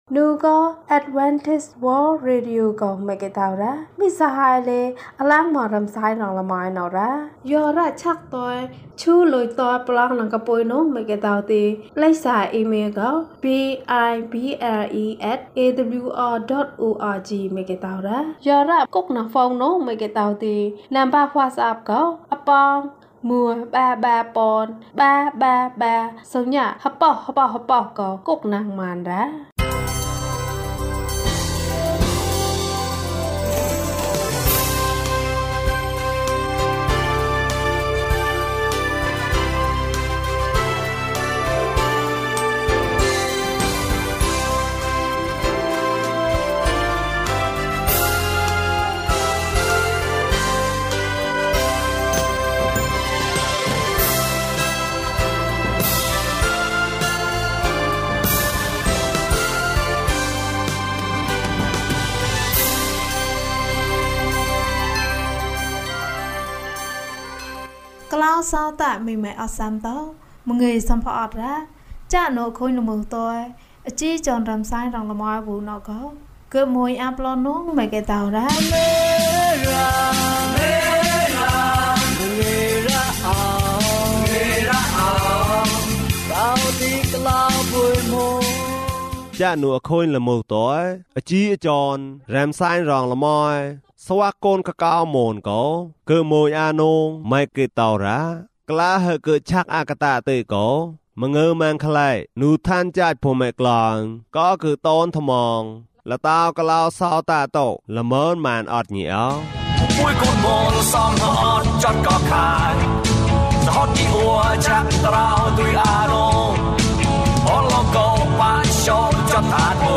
ခရစ်တော်ထံသို့ ခြေလှမ်း ၁၂။ ကျန်းမာခြင်းအကြောင်းအရာ။ ဓမ္မသီချင်း။ တရားဒေသနာ။